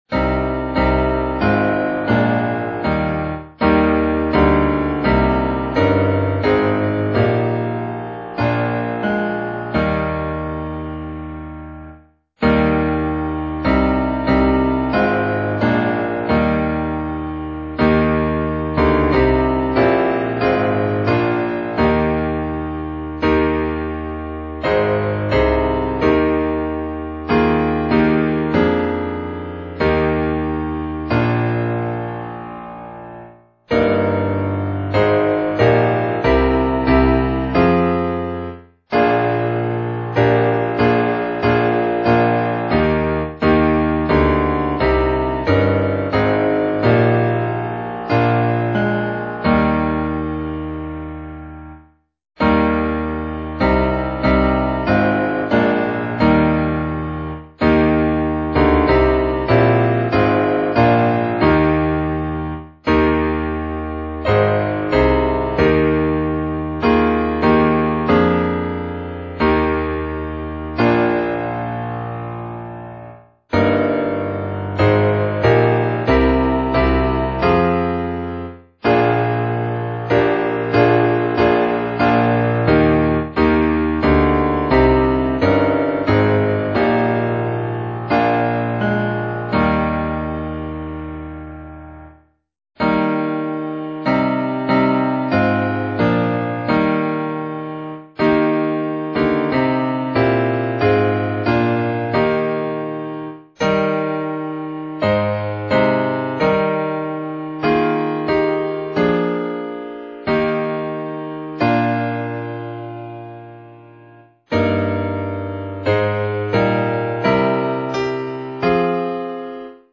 - UM CD.....UDM - DM Accompaniment.....